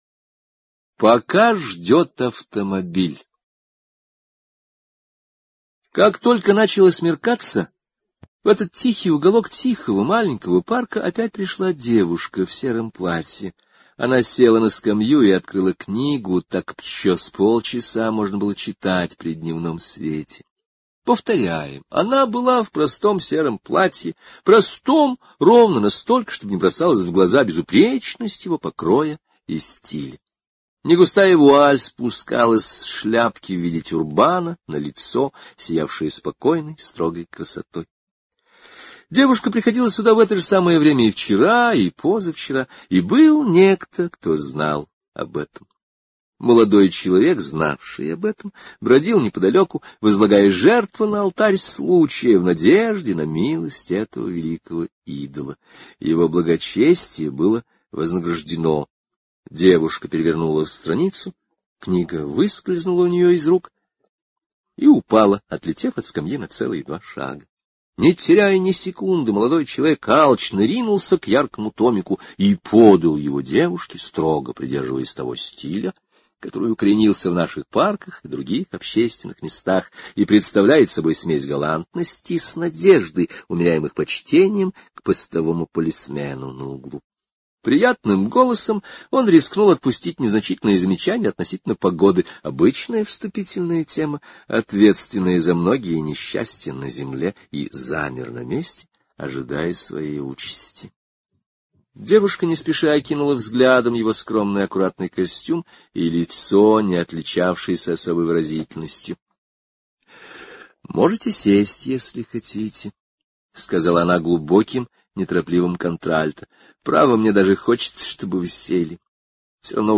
Пока ждёт автомобиль — слушать аудиосказку Генри О бесплатно онлайн